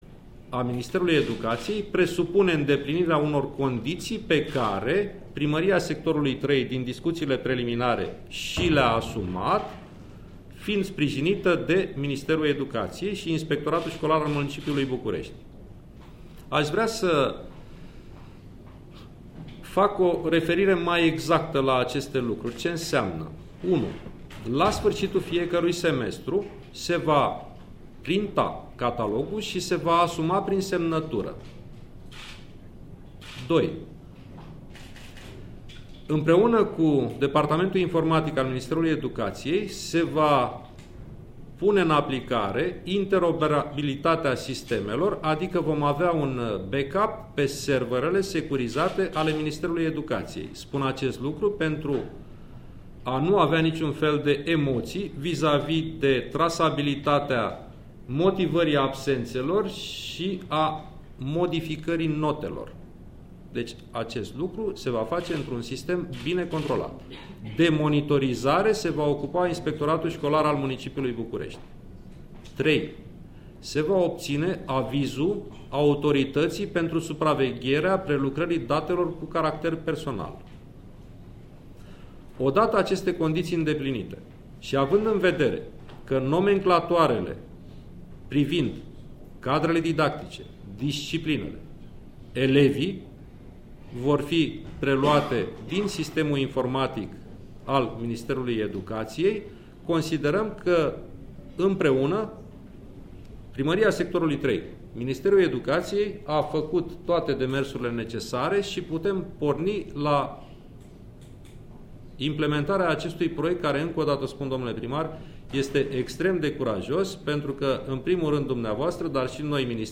Cum va functiona sistemul electronic “eCatalog” descris de Ministrul Educatiei Nationale Sorin Mihai Cimpeanu: